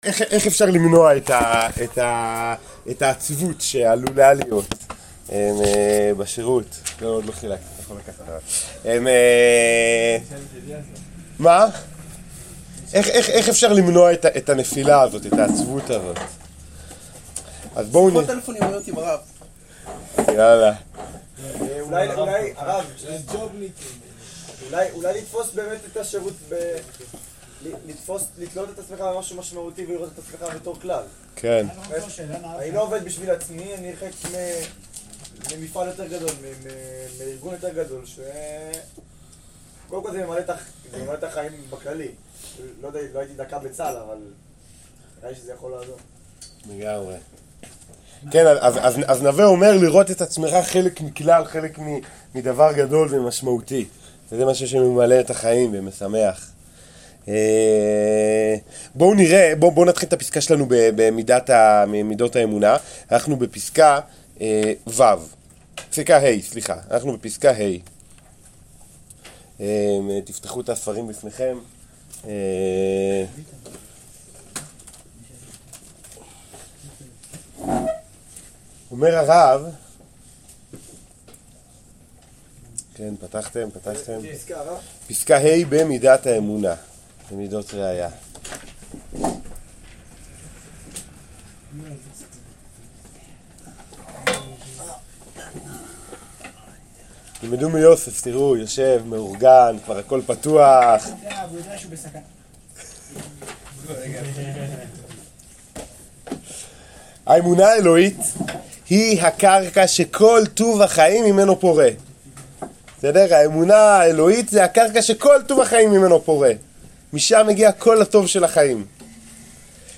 שיעור פסקה ה